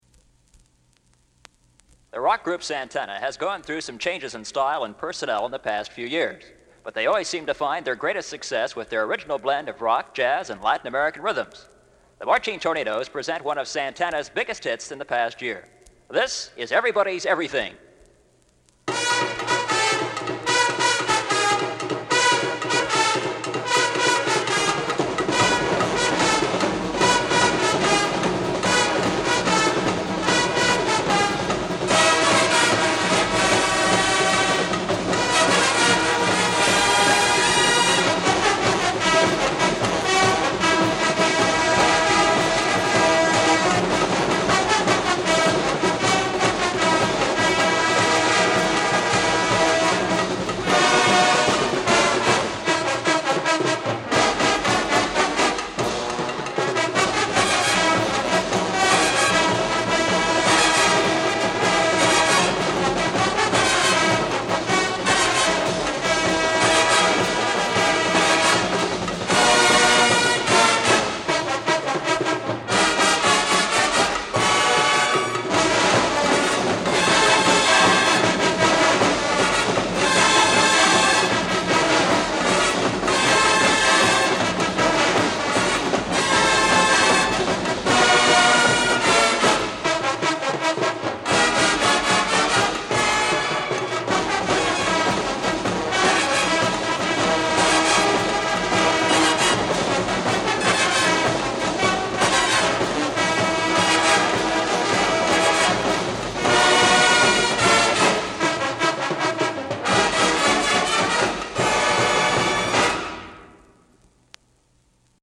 Marching Tornados Band
1973 Marching Tornados Band LP Recording